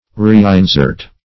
Reinsert \Re`in*sert"\ (r?`?n*s?rt"), v. t. To insert again.